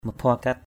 /mə-fʊa-kat/ (M. menghimpunkan)
maphuakat.mp3